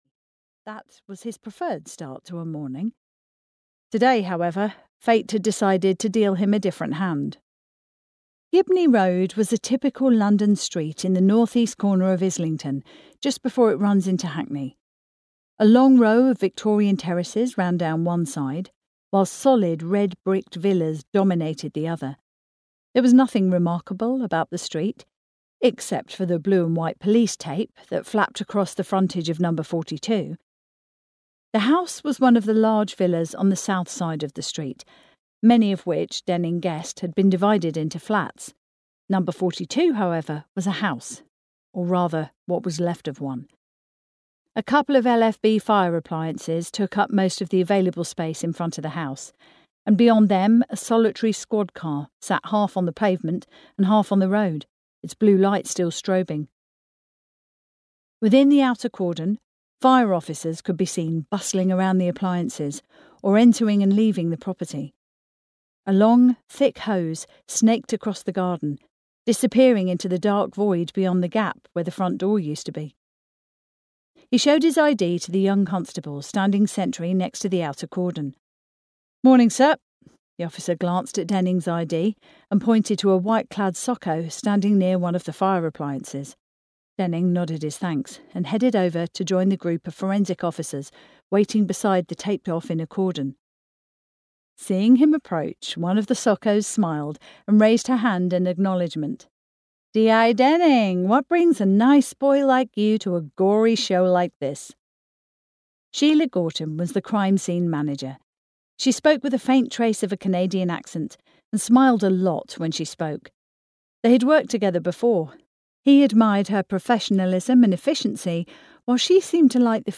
Blood Family (EN) audiokniha
Ukázka z knihy